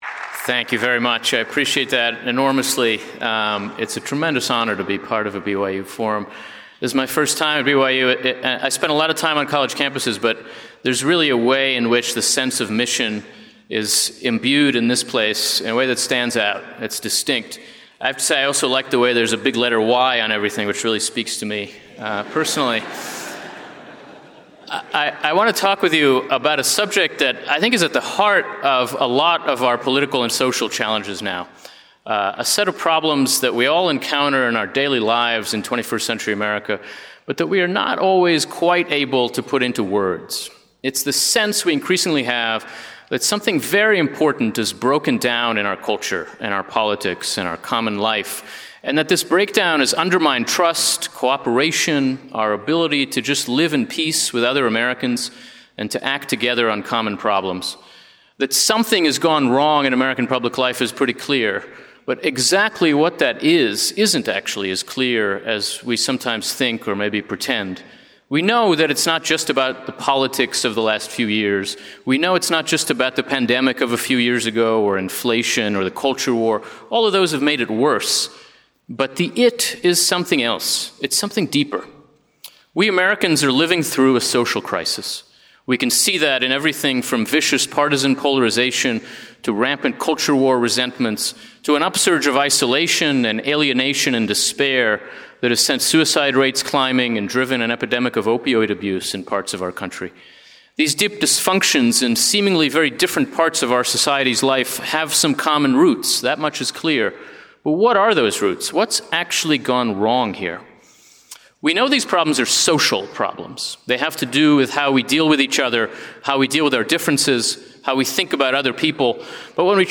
Yuval Levin, editor of National Affairs and director of social, cultural, and constitutional studies at the American Enterprise Institute, delivered this forum address on February 25, 2025.